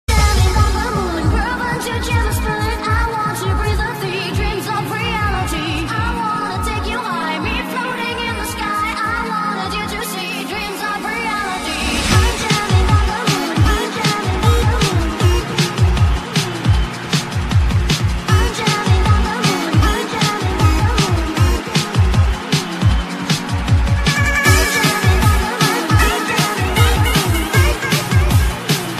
超劲爆DJ